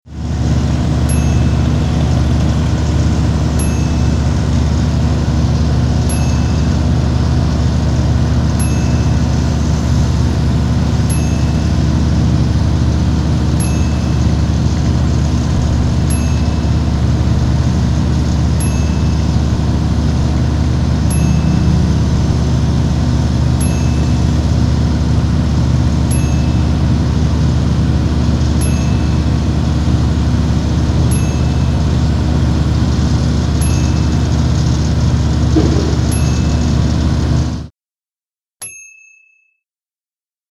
Semi Truck Idling (18 Wheeler)
Big 18 wheeler idling at a truck stop or intersection. Very clean recording with no unwanted noise.
SemiTruckIdling2.mp3